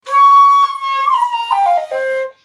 SONS ET LOOPS DE SHAKUHACHIS GRATUITS
Shakuhachi 43